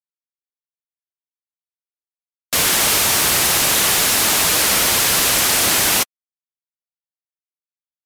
これでノイズの音だけが鳴るようになります。
ちなみにノイズの音なので音程がないため、どの音階に打ち込んでも同じ音がなると思います。
なんかもうちょっと音の終わりに余韻的なものが欲しいなーーーーーー。
ピタって音が切れるの嫌だなーーーーー。